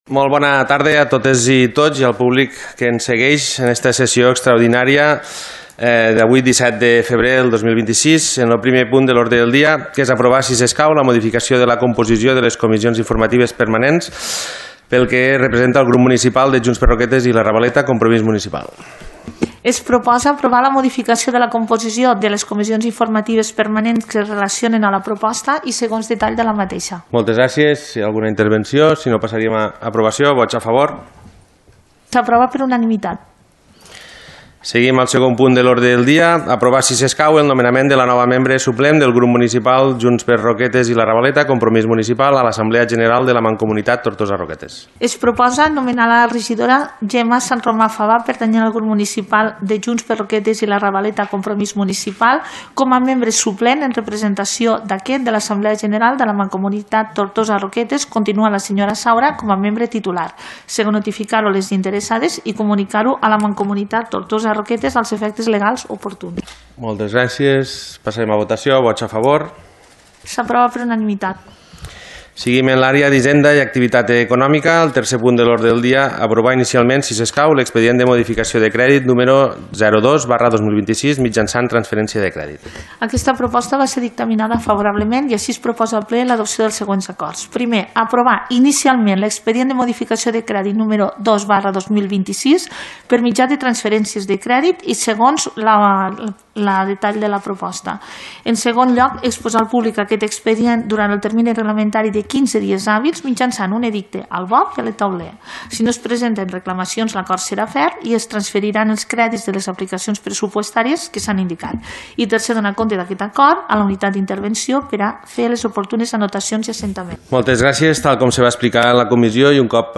Aquest dimarts 17 de febrer s’ha celebrat a la sala de sessions de l’Ajuntament de Roquetes el Ple extraordinari número 2/2026, corresponent al mes de febrer.